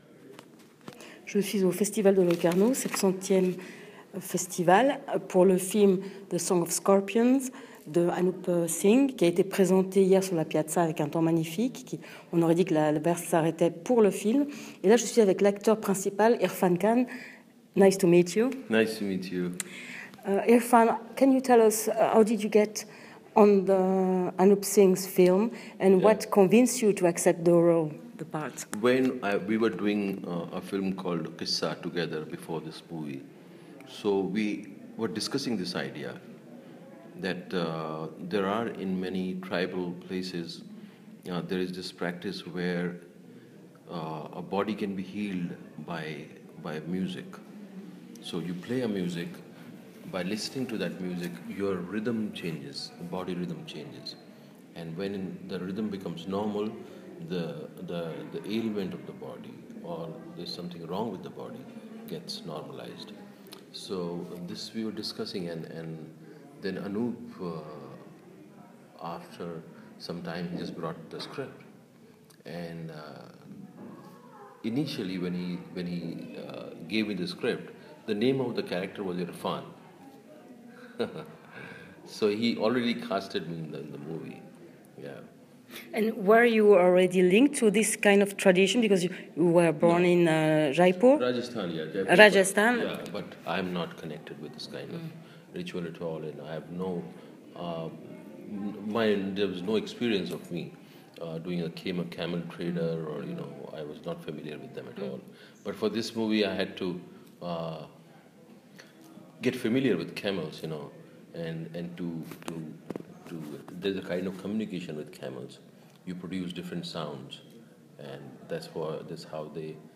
Interviews audio (en anglais)